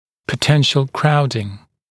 [pə’tenʃl ‘kraudɪŋ][пэ’тэншл ‘краудин]потенциальная скученность, возможная скученность